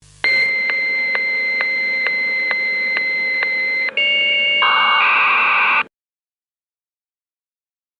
Modem Waiting for Connection
SFX
Modem Waiting for Connection is a free sfx sound effect available for download in MP3 format.
yt_ce3i-_8S5TQ_modem_waiting_for_connection.mp3